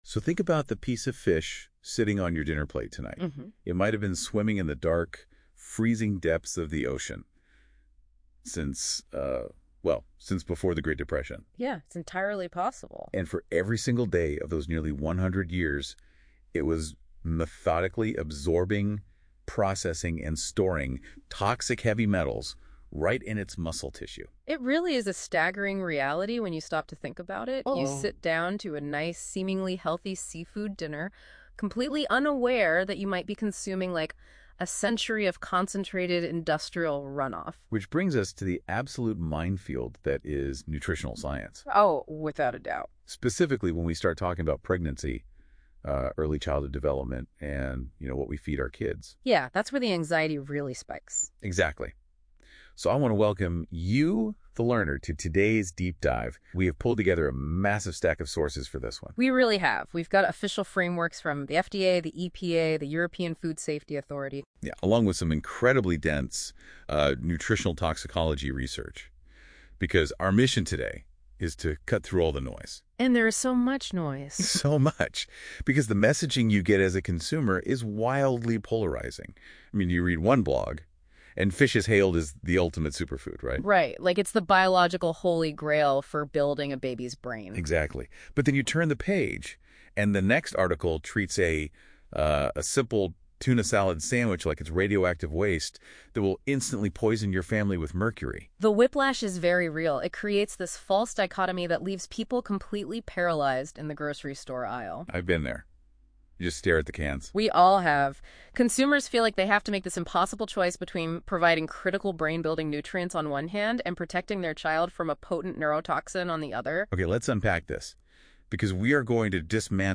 AI Podcast about this article by NotebookLM Download MP3 Disclaimer This article is for general informational purposes only.